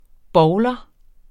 Udtale [ ˈbɒwlʌ ]